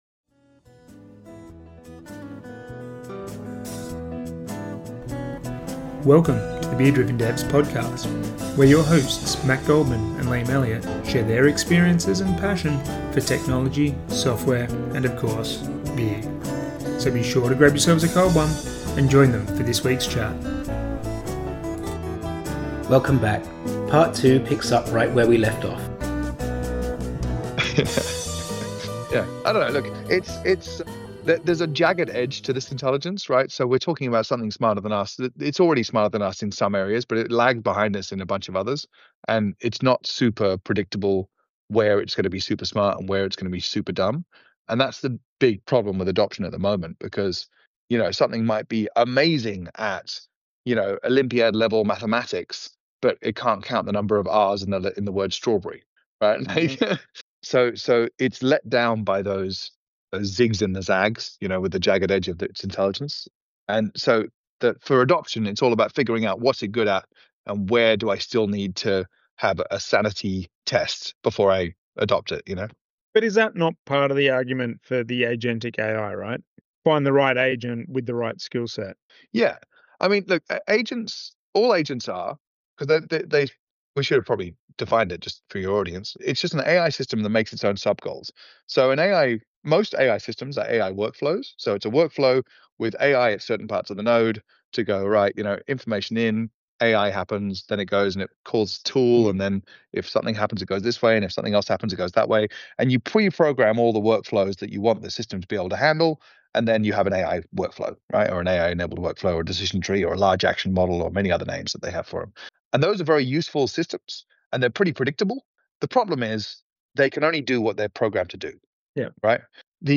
with plenty of laughs along the way.